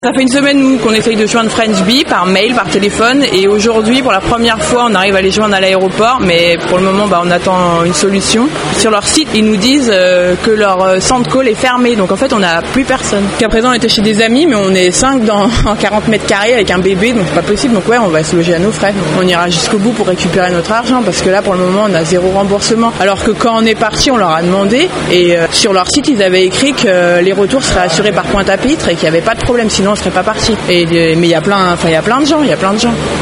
AEROPORT-2-touriste.mp3